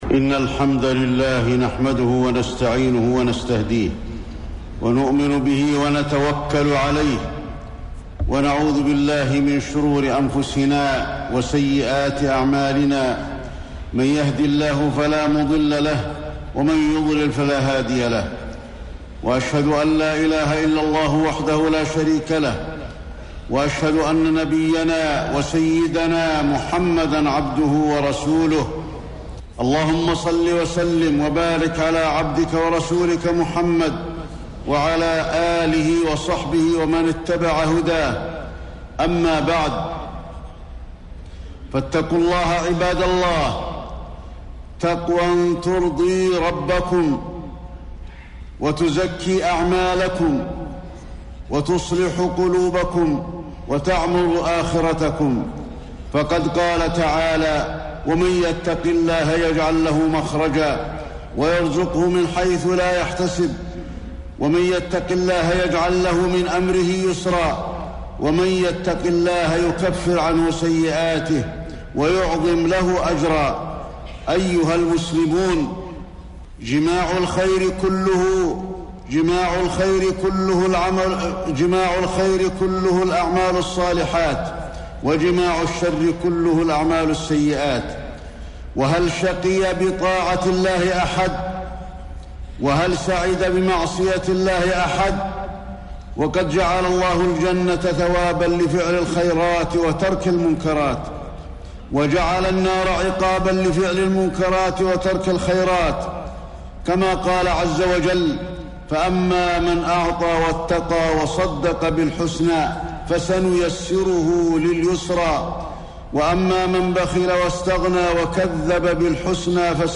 خطبة الجمعة 3-6-1432 هـ | موقع المسلم
خطب الحرم المكي